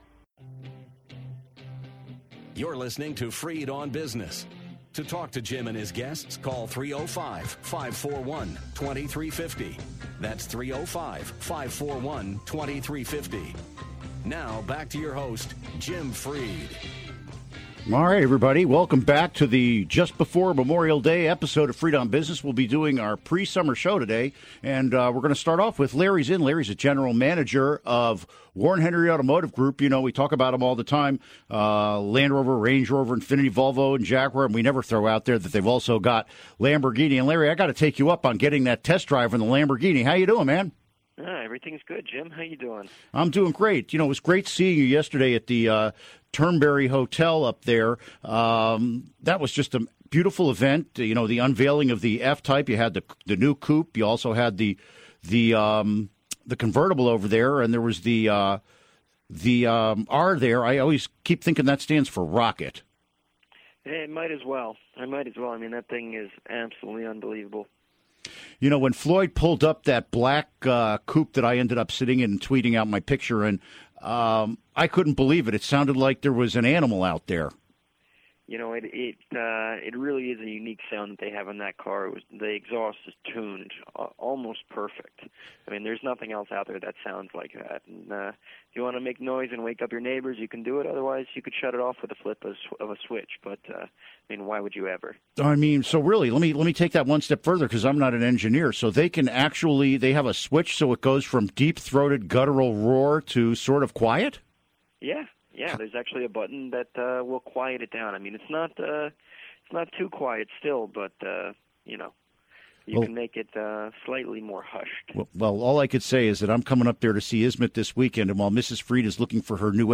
Interview Segment